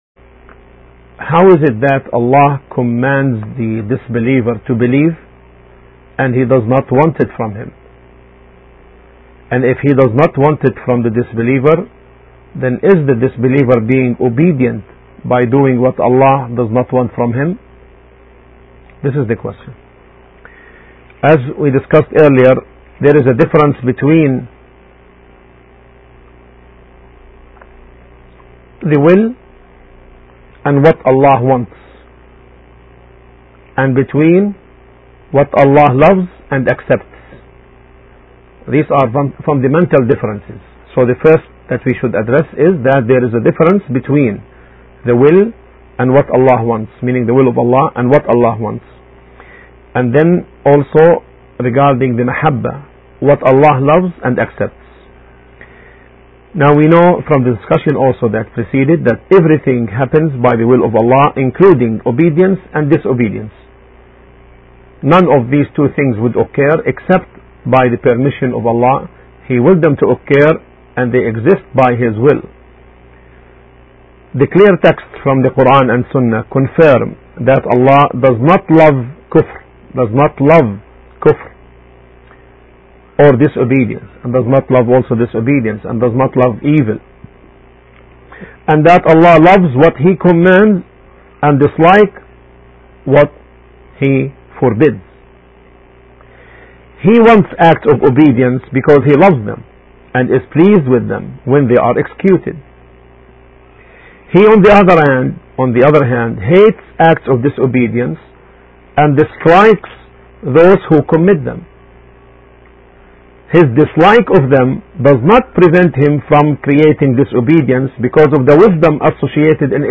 We should understand this pillar based on the Quranic verses and the authentic Sunnah not based on personal opinions. Pre-decree of Allah has four ranks: knowledge, writing, willing and creation. The lecturer answers an important question: